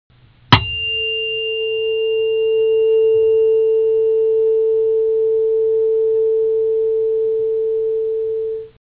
Tuning fork 3
Category: Sound FX   Right: Personal
Tags: Tuning Fork Tuning Fork sounds Tuning Fork clips Tuning Fork sound Sound effect